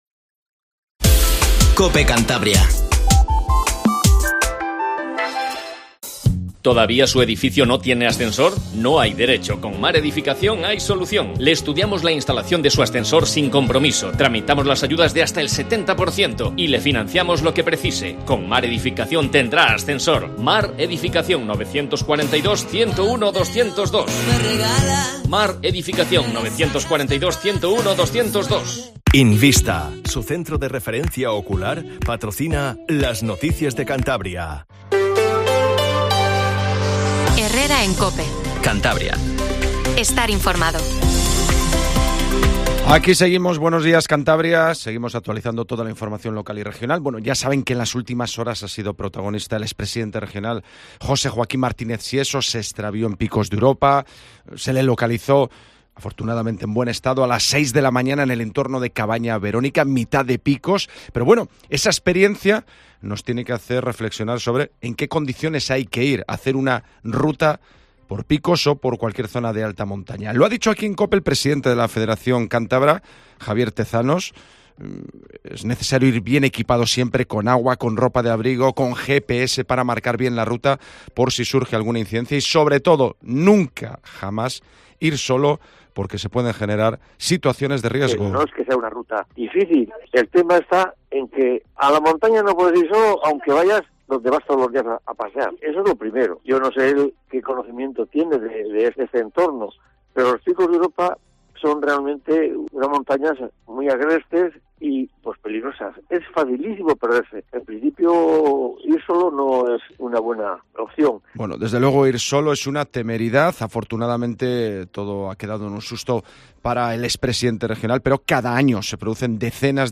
Informtivo Matinal COPE CANTABRIA